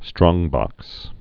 (strôngbŏks)